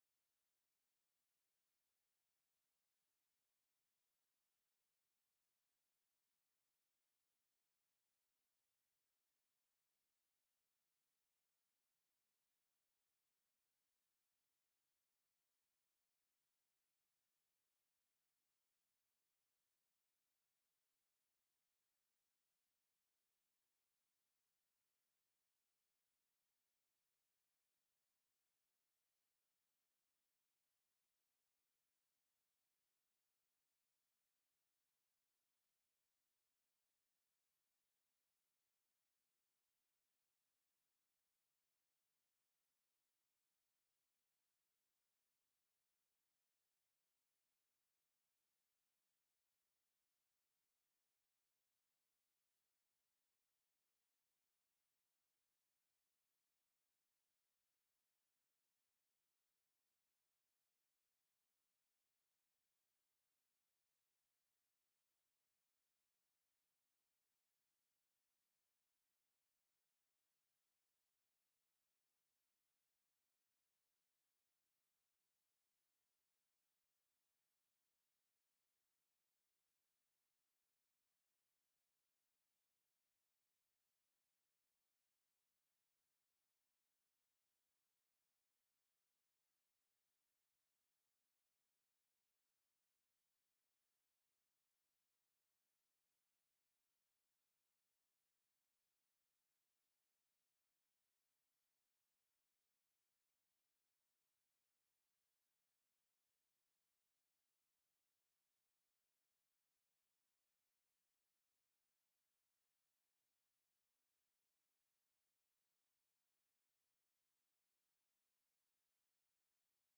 Our talented worship team helps us give praise to the Lord this Sunday morning.